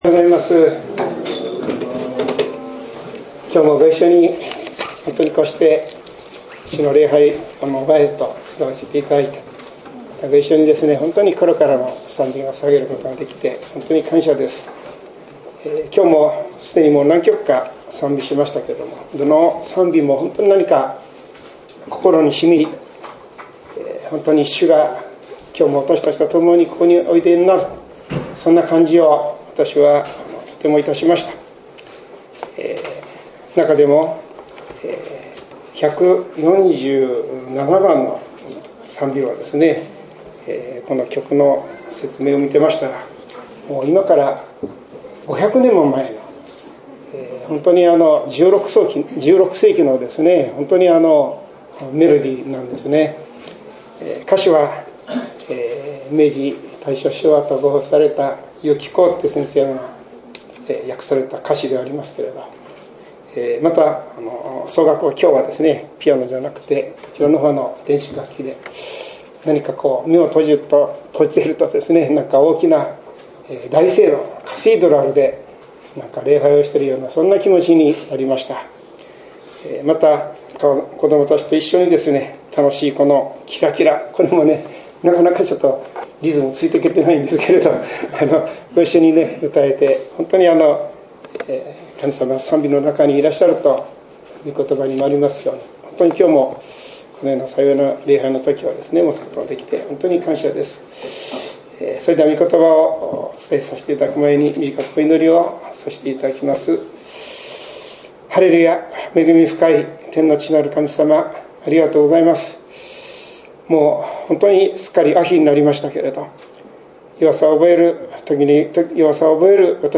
メッセージ